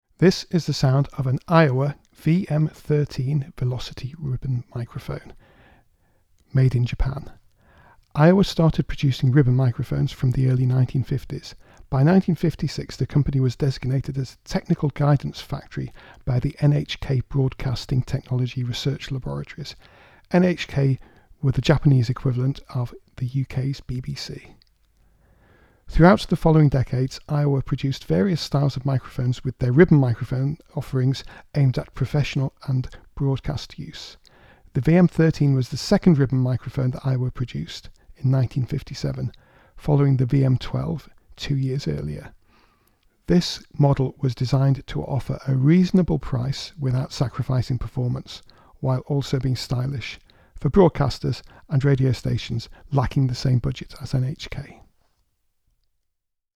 Here we have a small ribbon microphone from Aiwa in nice working condition.
Sound clips – speech (me!)
Aiwa_VM13_Speech.mp3